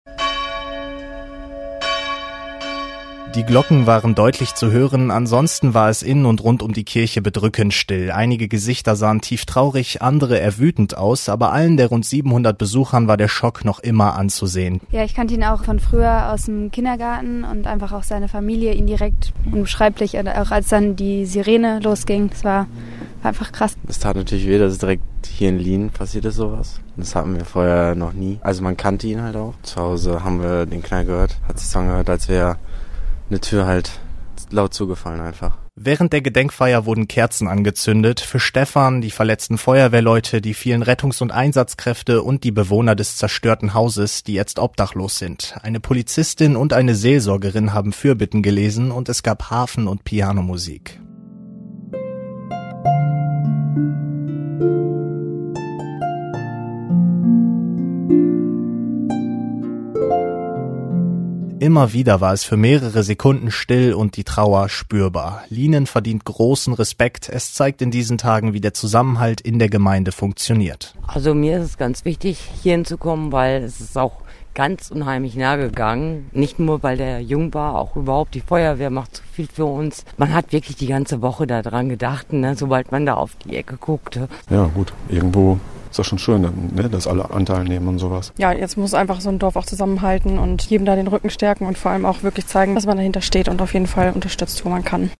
In Lienen haben die Menschen am Abend (12.02.20) mit einem ökumenischen Gottesdienst des getöteten Feuerwehrmanns gedacht.
bmo_-_gedenkgottesdienst_lienen.mp3